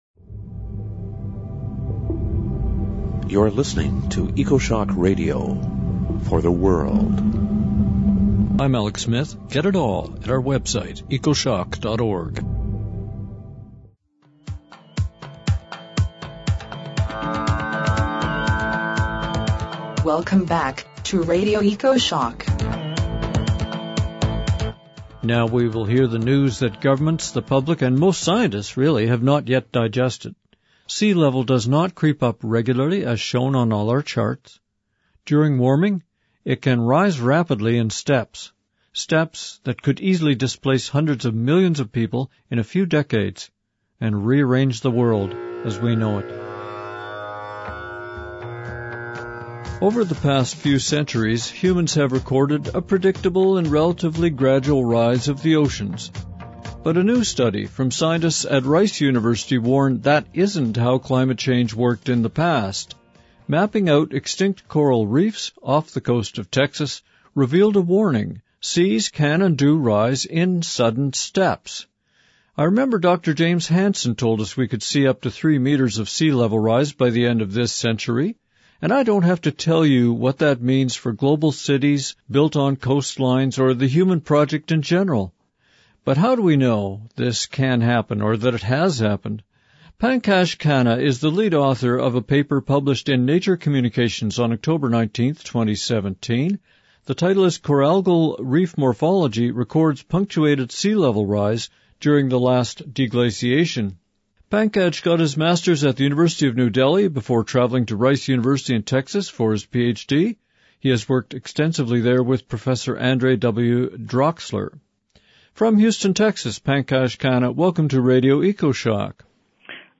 We talk with lead author